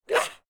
femalezombie_attack_04.ogg